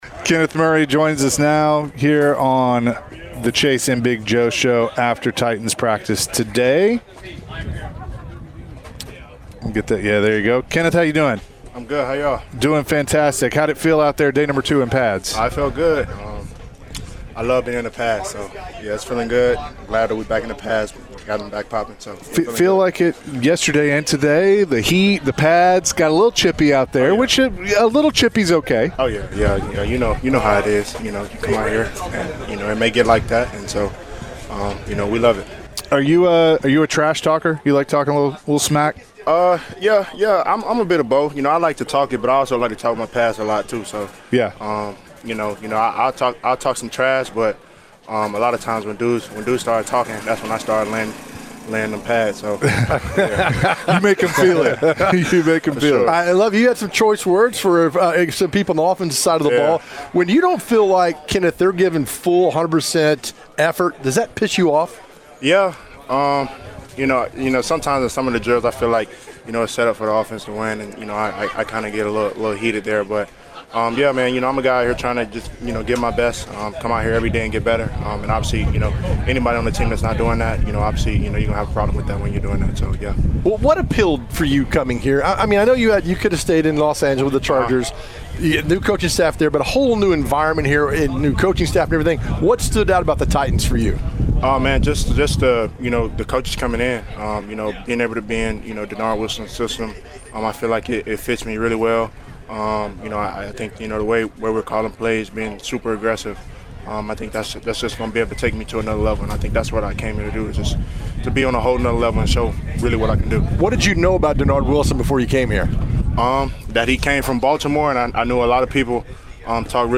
new Titans linebacker Kenneth Murray joined the show after practice and shared his thoughts about being on the team.